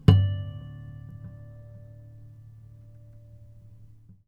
harmonic-05.wav